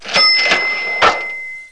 cashreg.mp3